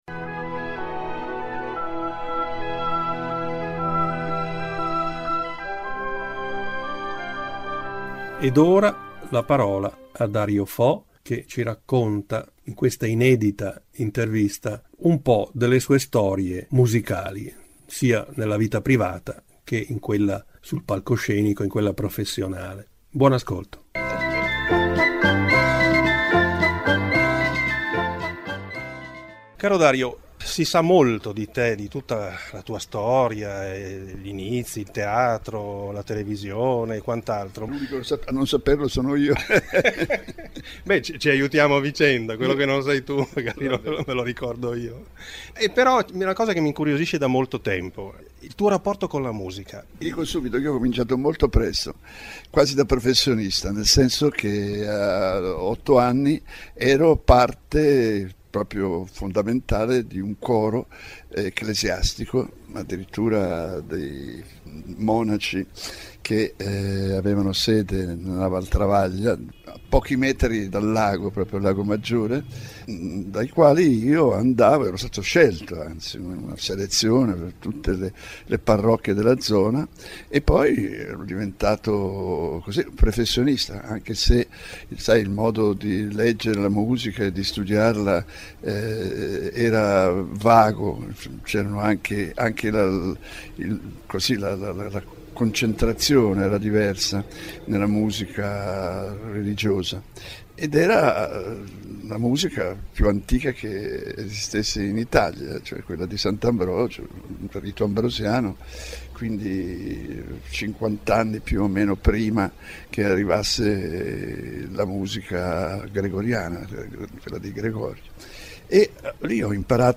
Ballate, ritmi, improvvisazioni: il battito di un giullare moderno
La prima puntata Dario Fo in musica è a casa di Dario e Franca in Corso di Porta Romana, a Milano nel 2009, perché volevo sapere come, dove e quando la musica è entrata nella sua vita privata e professionale, arricchendole entrambe con il contributo di importanti musicisti, soprattutto Enzo Jannacci e Fiorenzo Carpi.
In finale voci, suoni e musiche da piazza del Duomo sotto una pioggia battente: era il 15 ottobre 2016, l’ultimo saluto di Milano a Dario Fo….